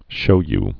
(shōy)